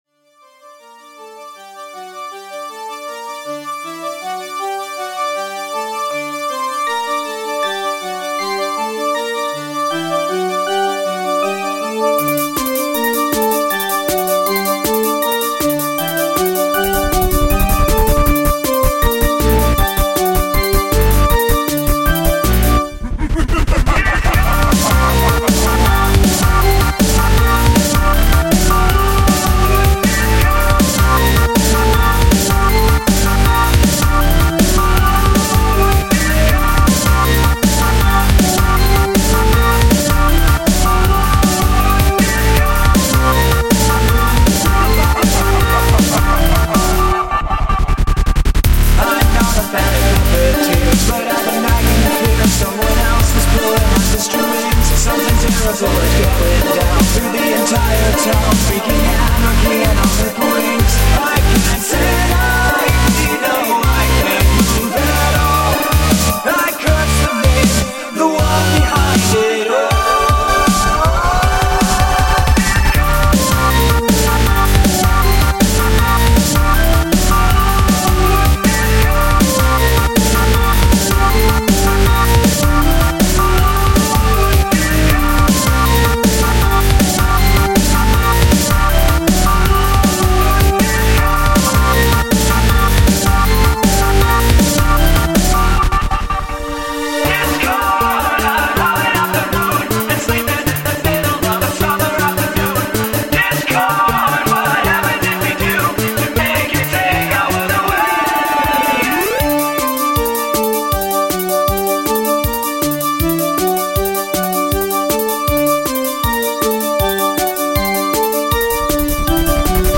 FAQ: Yes, it does sound like the original.
genre:remix